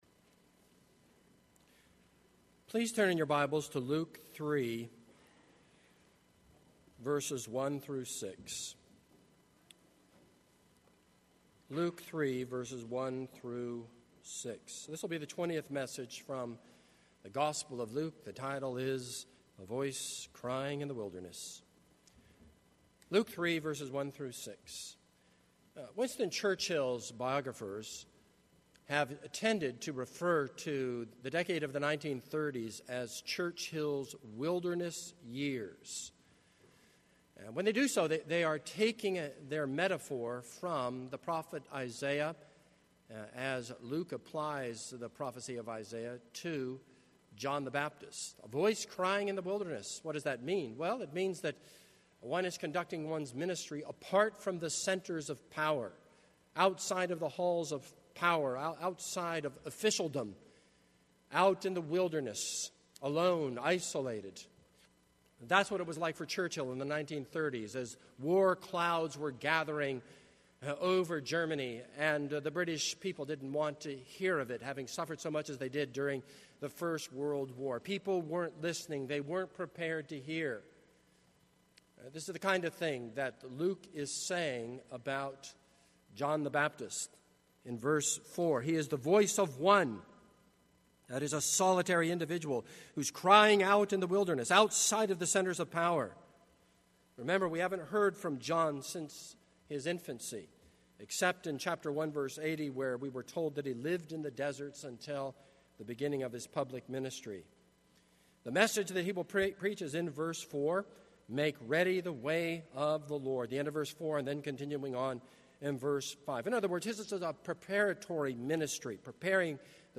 This is a sermon on Luke 3:1-6.